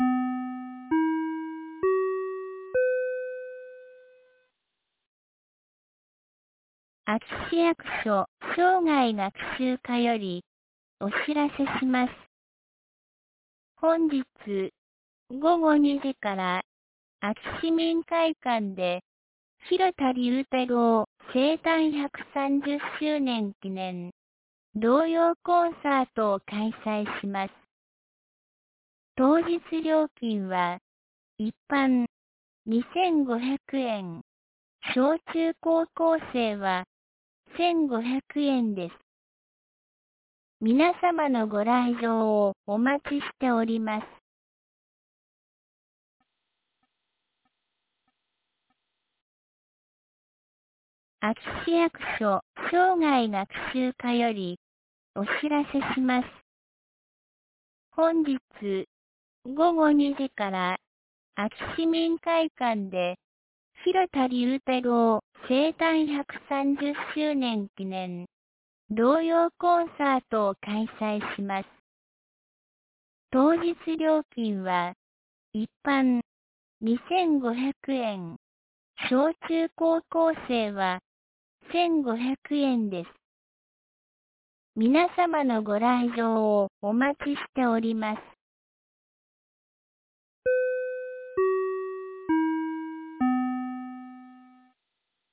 2022年10月10日 10時31分に、安芸市より全地区へ放送がありました。